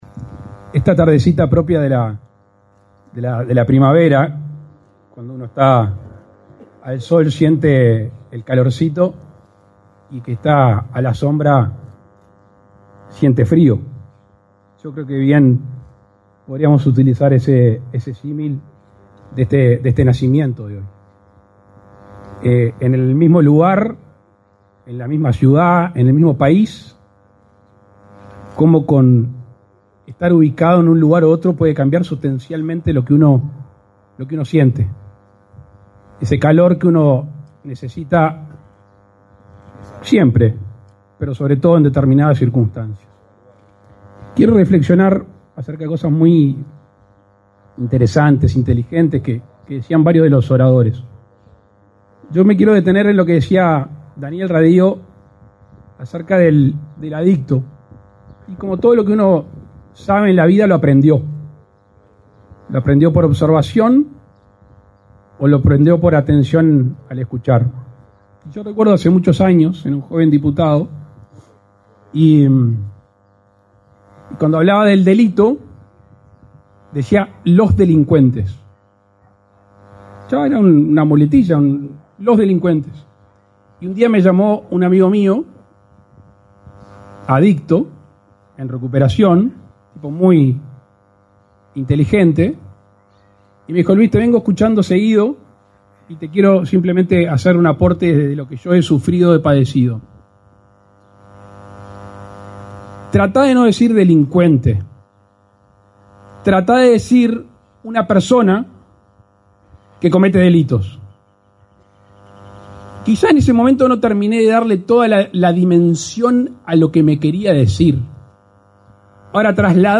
Palabras del presidente de la República, Luis Lacalle Pou, en Florida
El presidente de la República, Luis Lacalle Pou, participó, este 27 de setiembre, en la inauguración del centro diurno para personas que padecen